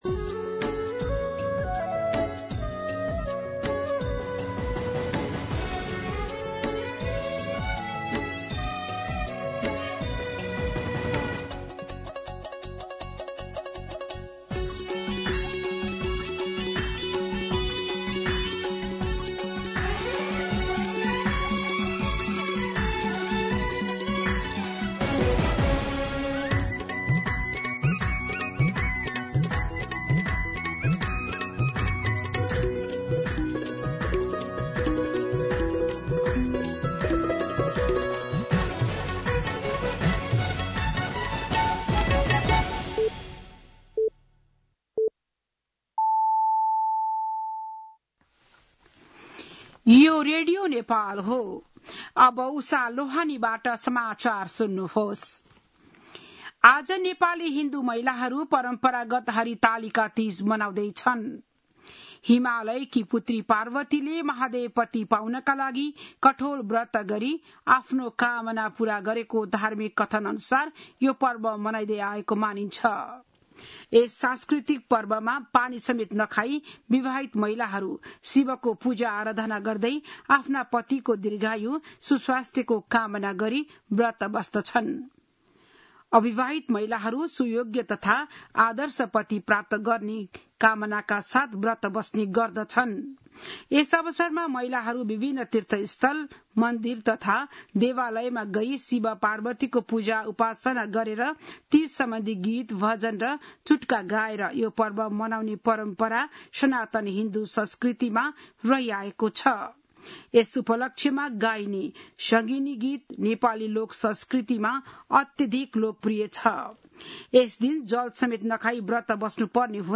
बिहान ११ बजेको नेपाली समाचार : १० भदौ , २०८२